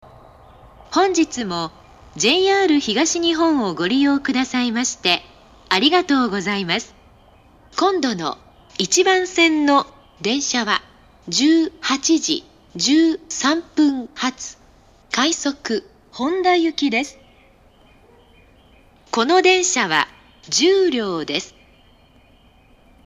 ２０１６年６月中旬頃には放送装置が更新され、発車メロディーの音質が向上しました。
平日の夕方以降は鳴りやすくなりますが、曲の余韻が長いので余韻切りが多いです。